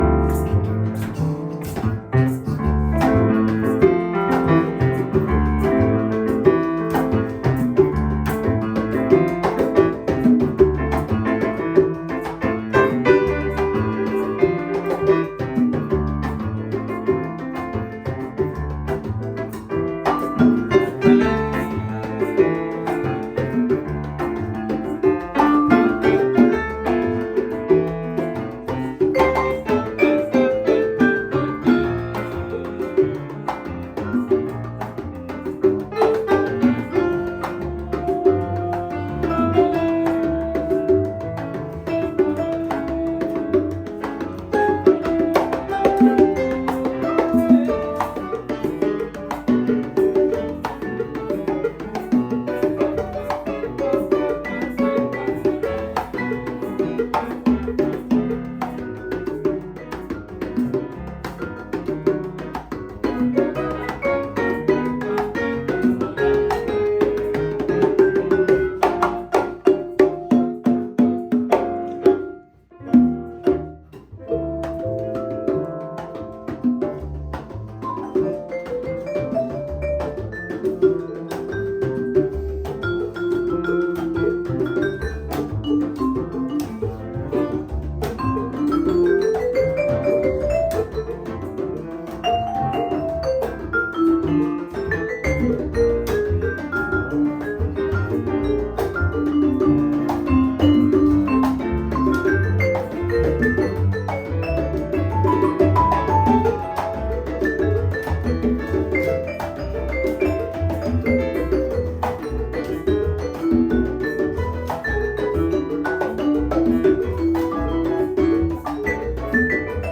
Latin Jazz
vibraphone
piano et autres instruments
percussions
basse